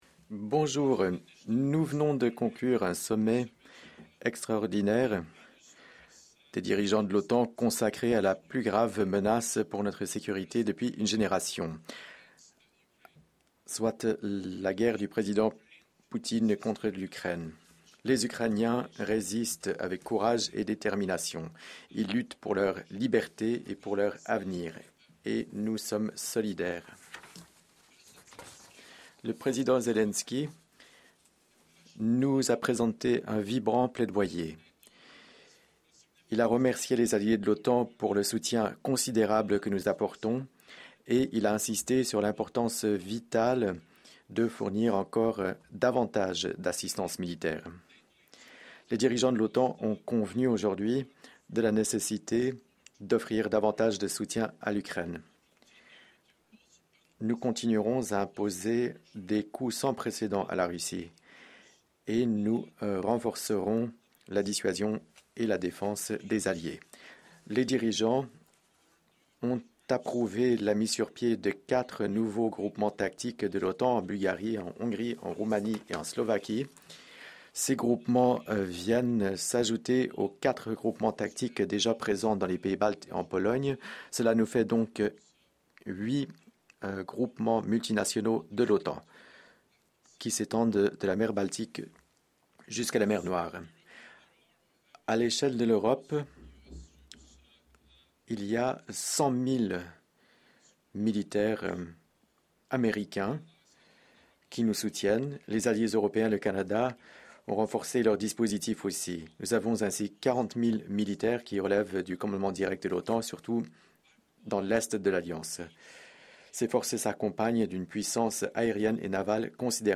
Press conference
by NATO Secretary General Jens Stoltenberg following the extraordinary Summit of NATO Heads of State and Government